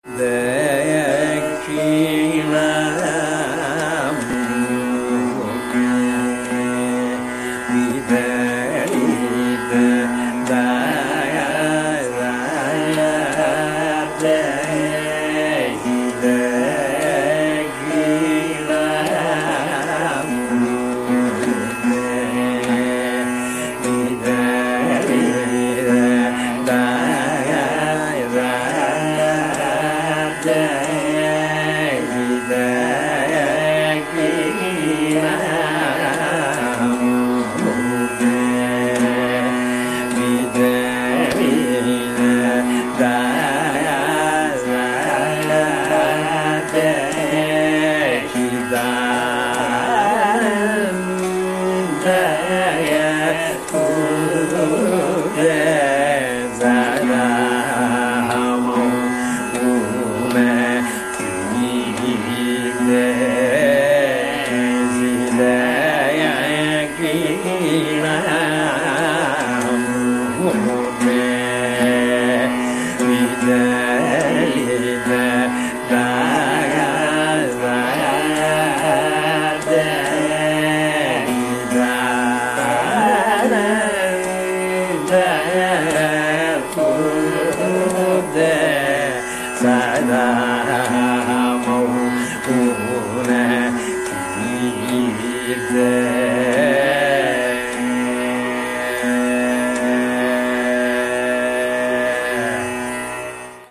Pallavi begins.
Each of the two lines of the pallavi repeats and each has its own melodic characteristics, both remaining in the purvānga.
The performers introduce variations during the repeats.